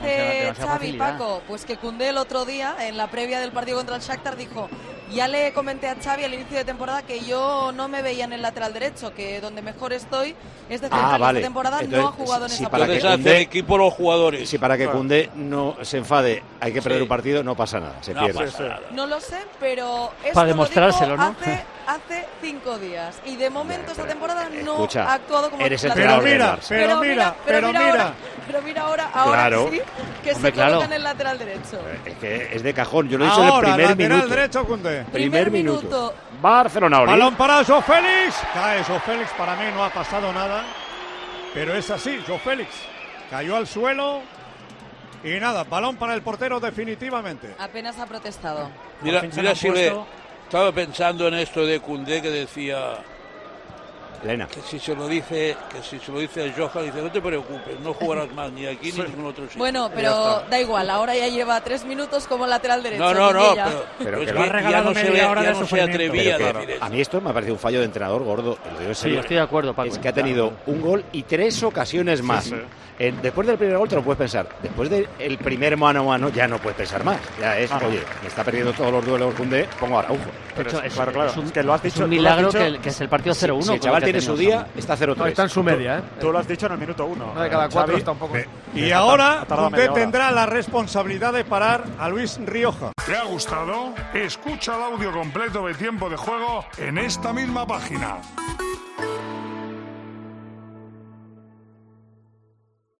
Disposición táctica de Xavi que recibió críticas durante la retrasmisión del encuentro en Tiempo de Juego, tanto es así que el director y presentador del programa líder de la radio deportiva española, Paco González, pidió un cambio al técnico catalán: "Desde el minuto 1".
El recado táctico de Paco González a Xavi durante el Barcelona-Alavés: Curiosamente